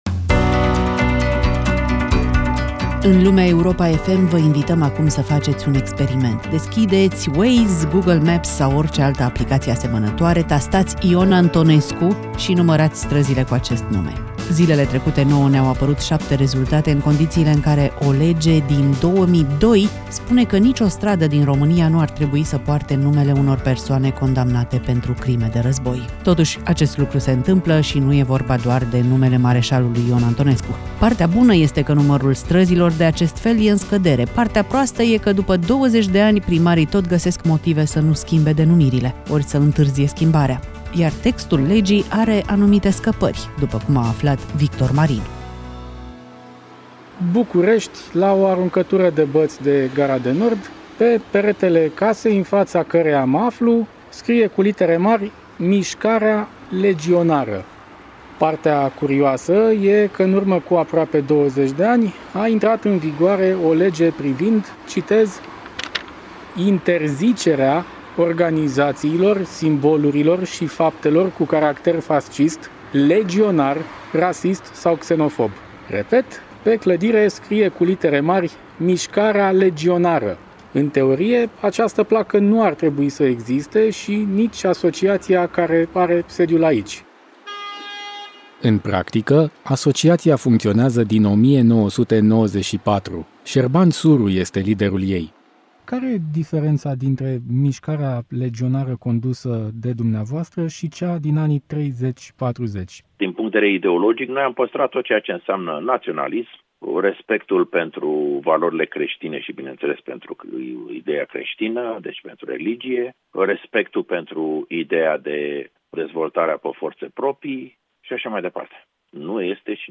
Lumea Europa FM: Străzile și legionarii – mic manual de fentare a legii | REPORTAJ